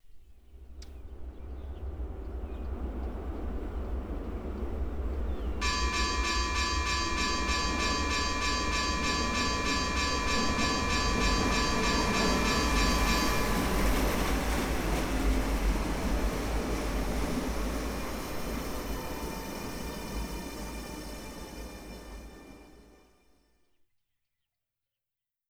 Paso a nivel cuando un tren esta pasando: campana de aviso
tren
campana
Sonidos: Transportes